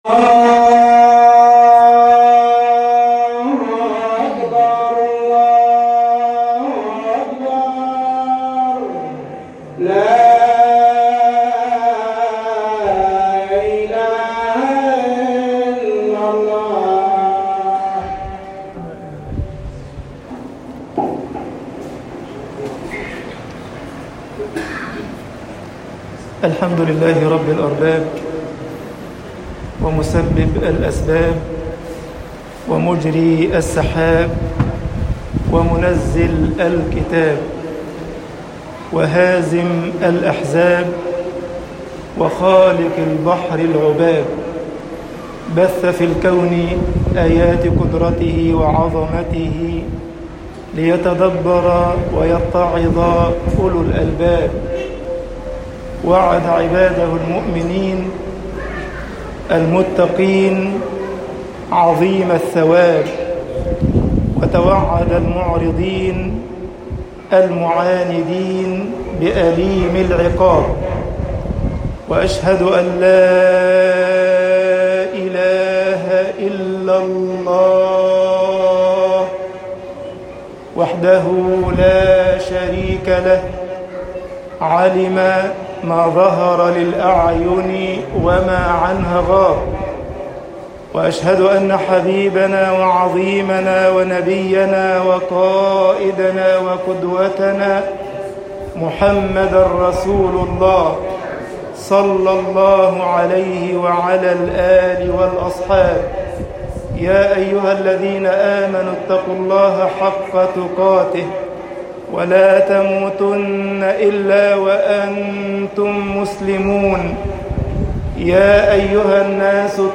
خطب الجمعة - مصر وَمَا أرْسَلْنَاكَ إلَّا رَحْمَةً للعَالَمِين طباعة البريد الإلكتروني التفاصيل كتب بواسطة